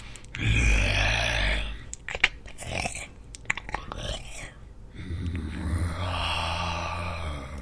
四通道环绕声效果 " 僵尸洗牌与呻吟1
标签： 呻吟 INT 呻吟 呻吟 环绕声 洗牌 僵尸
声道立体声